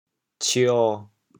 潮州 ciê7 潮阳 cio7 饶平 cionn7 汕头 cionn7 潮州 0 潮阳 0 饶平 0 汕头 0